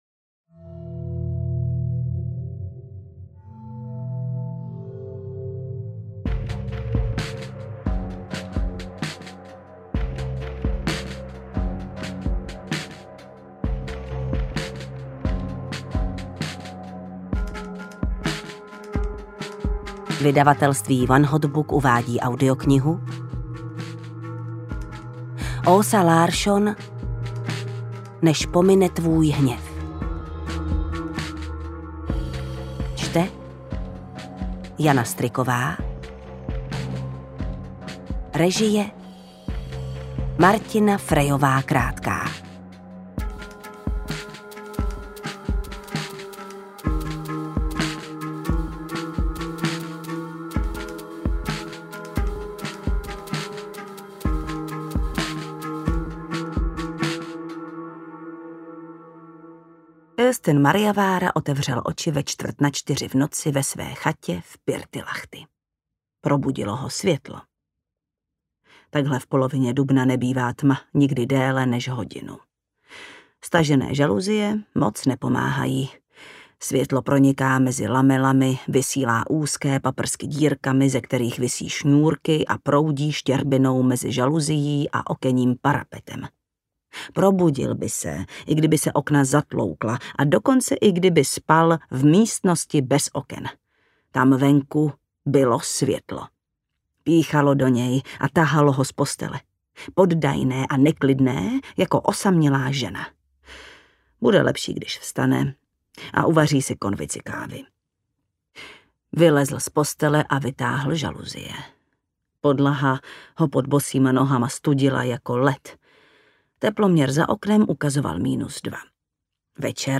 Než pomine tvůj hněv audiokniha
Ukázka z knihy